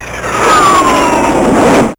JetPass.wav